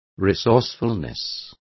Complete with pronunciation of the translation of resourcefulness.